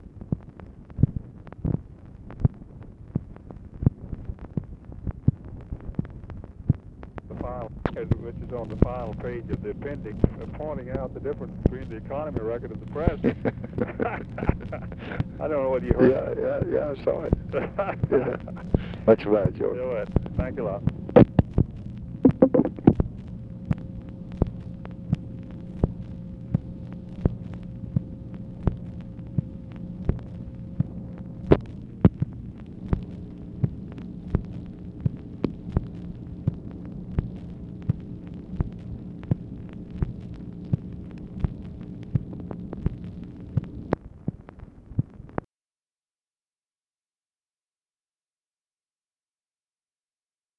Conversation with GEORGE MAHON, August 21, 1965
Secret White House Tapes